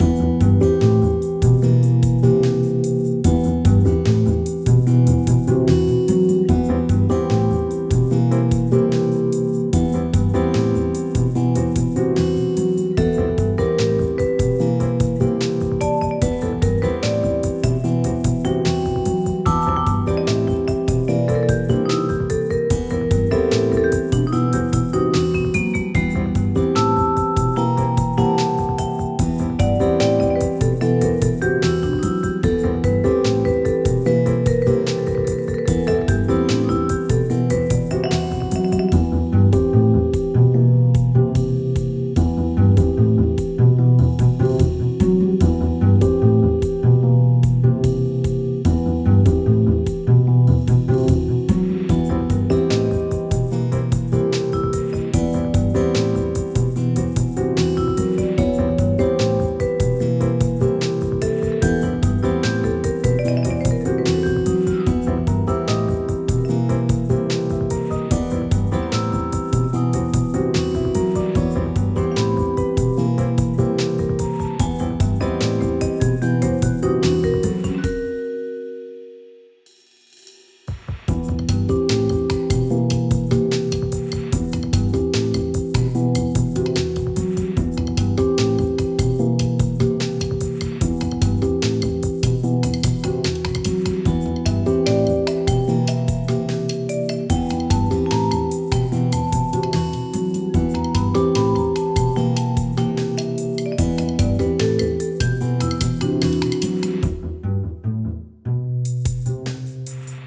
heavy_atmosphere.ogg